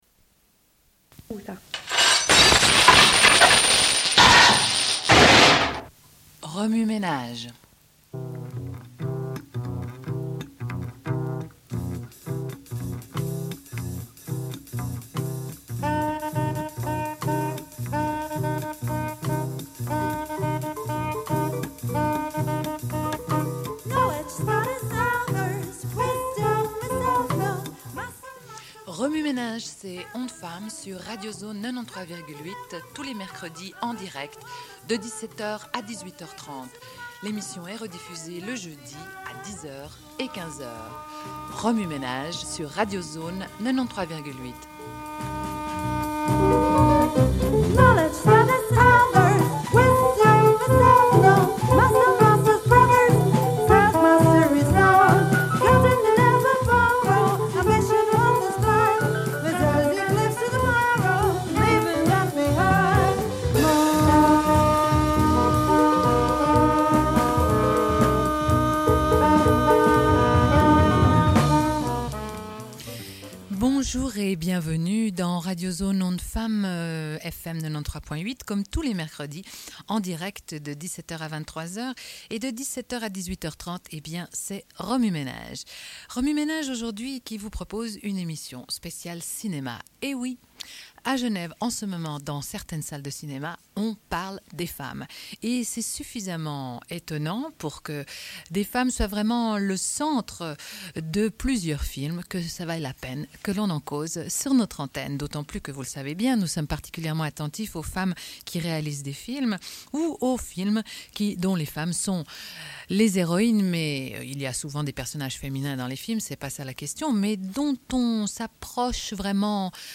Une cassette audio, face A31:21